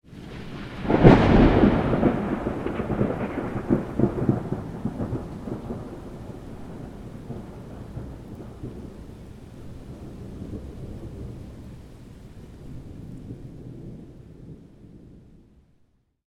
thunder_25.ogg